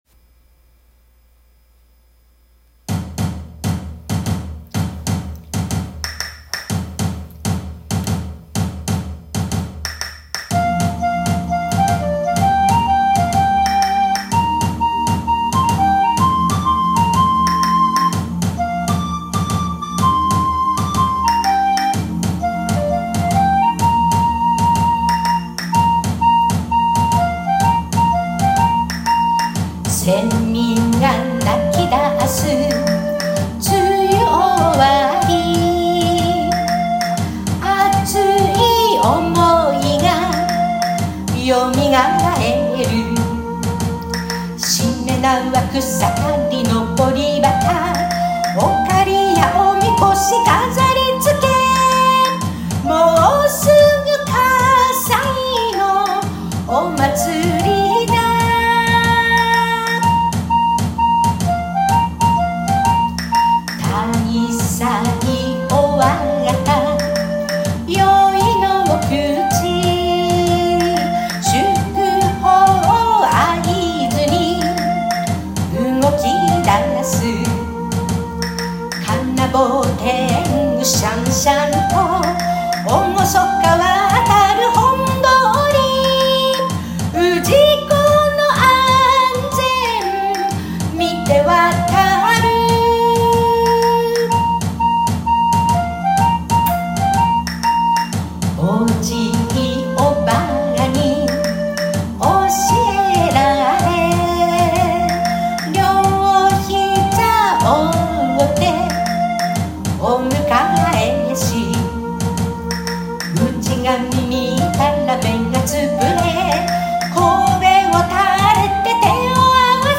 笠井まつりを音頭で歌い上げています。